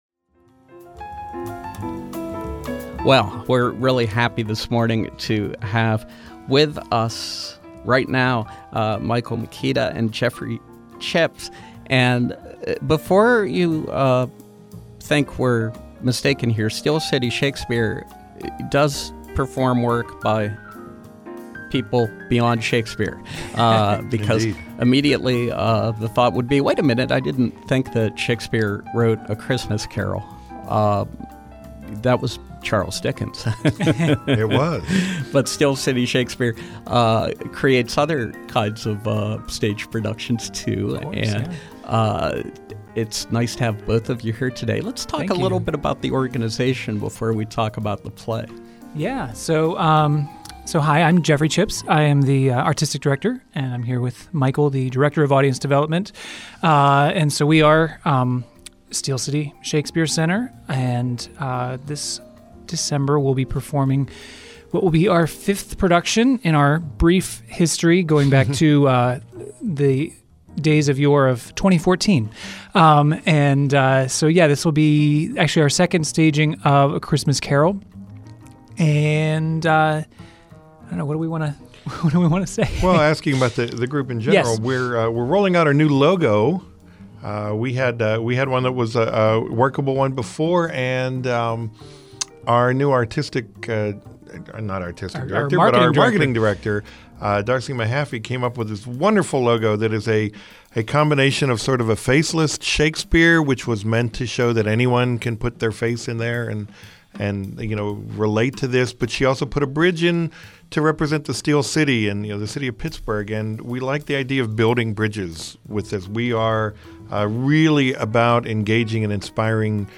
Interview: Steel City Shakespeare, A Christmas Carol